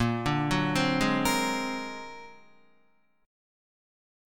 Bb+9 chord